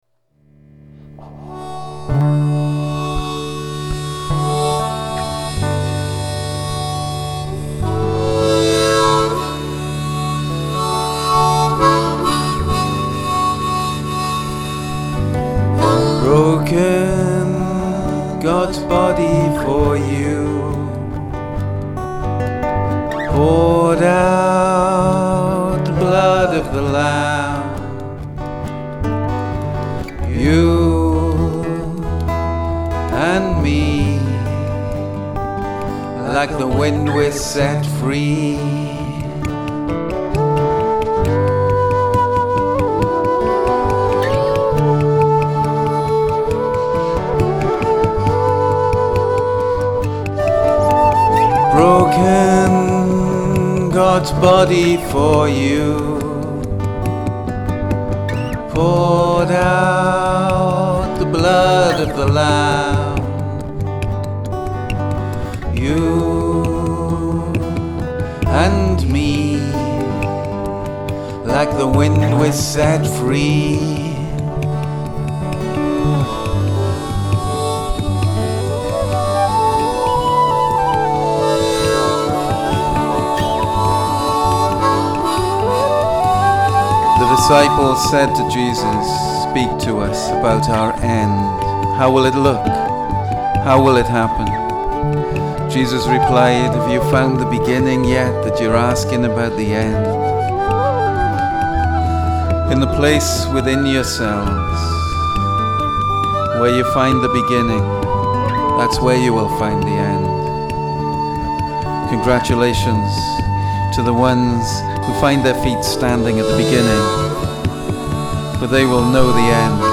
Maybe the final mix of this track at last.
flautist